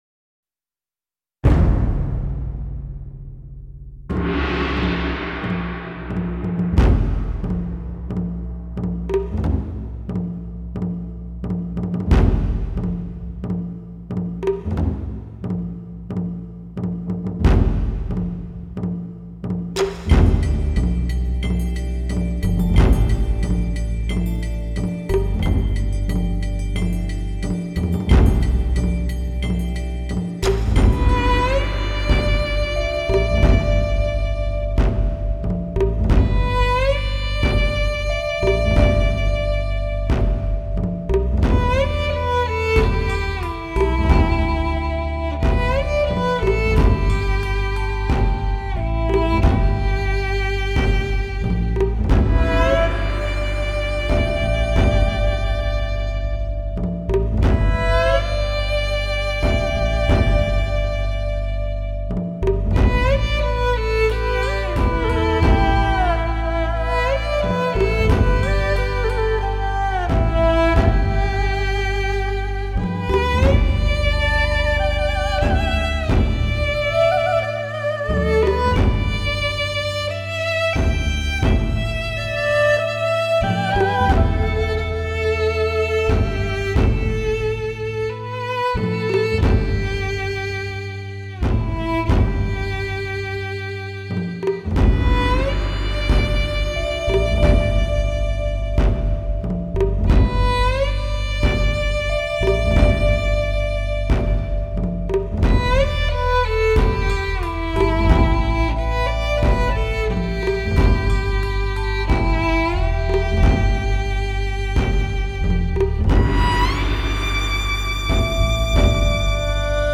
人声吟唱
从容不迫 生动跳跃 表现一流
既无可闻性的单薄感，也无分解力不足的混浊感，